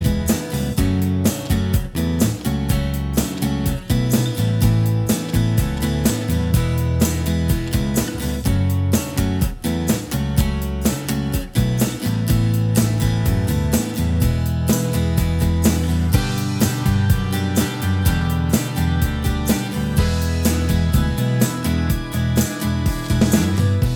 Minus Lead Guitar Indie / Alternative 4:02 Buy £1.50